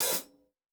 Closed Hats
TC Live HiHat 08.wav